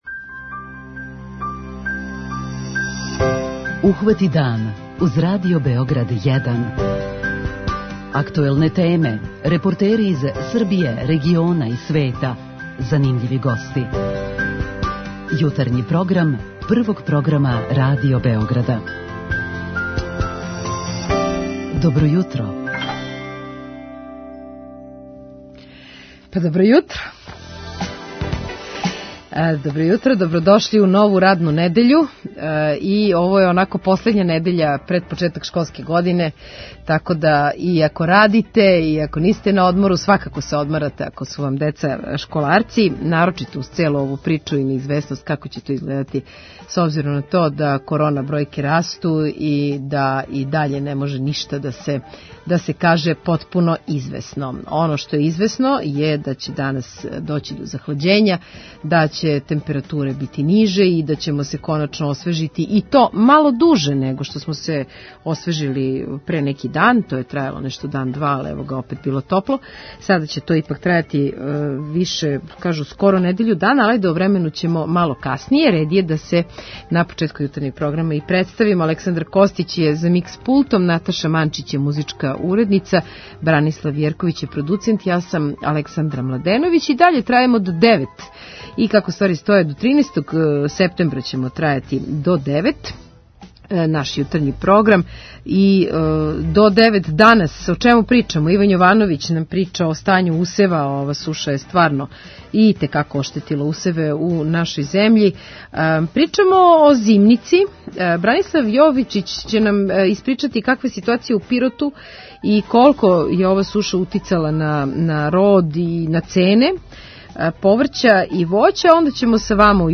Јутарњи програм Радио Београда 1!